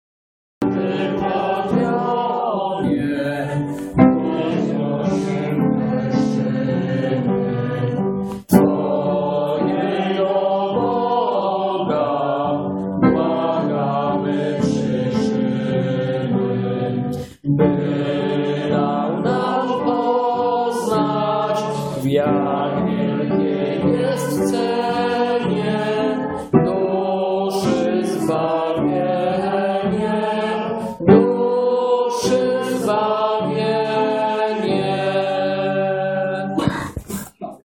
uproszczone pod śpiew z ludem.